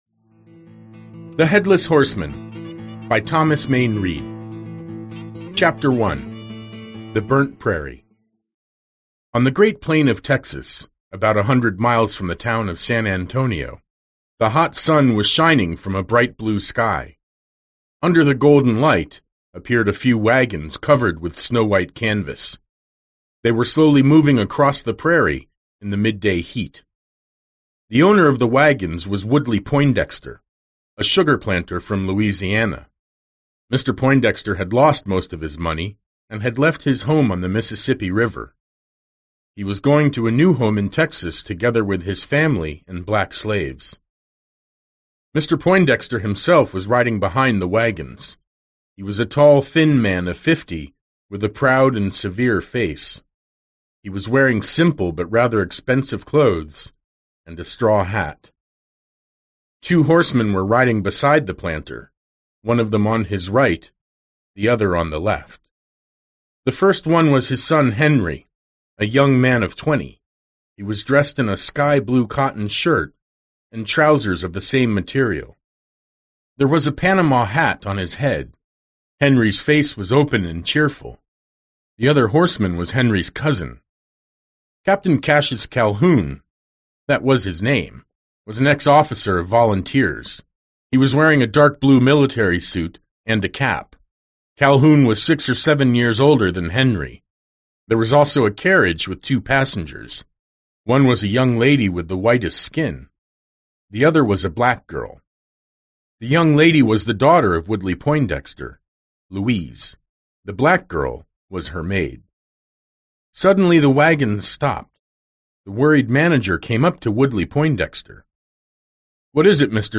Аудиокнига Всадник без головы / The Headless Horseman | Библиотека аудиокниг